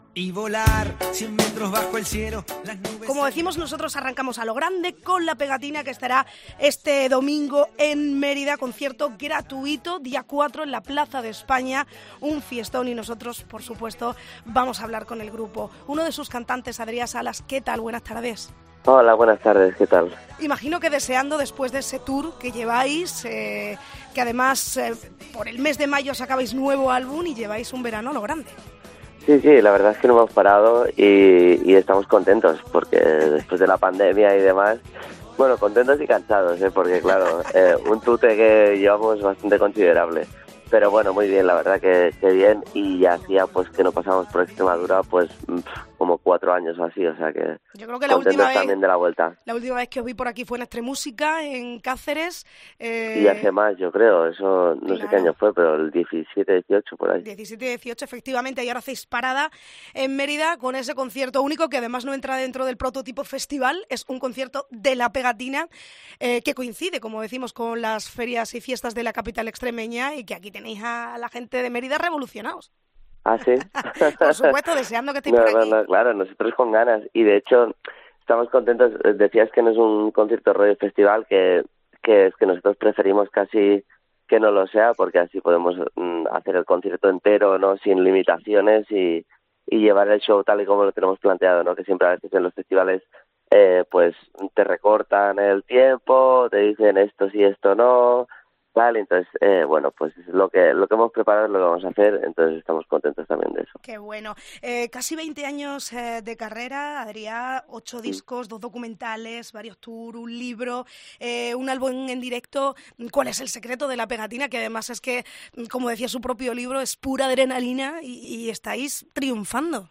Entrevista a Adriá Salas de La Pegatina